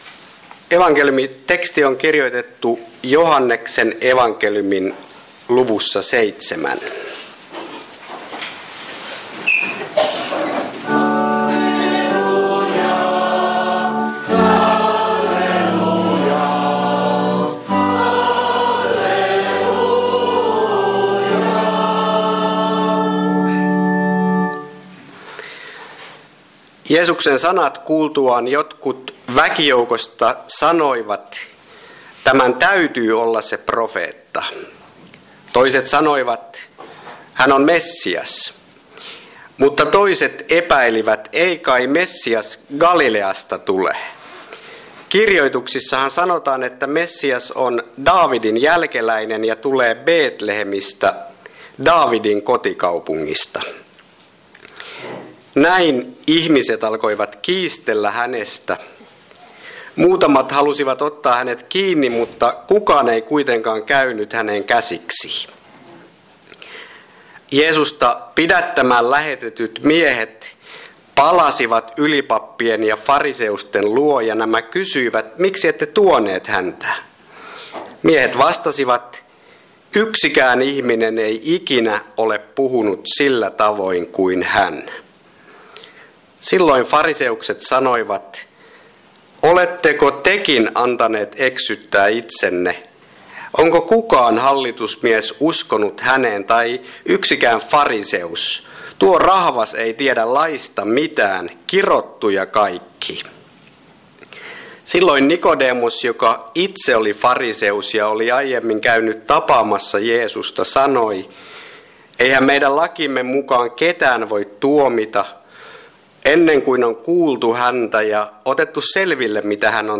Kokoelmat: Seinäjoki Hyvän Paimenen kappelin saarnat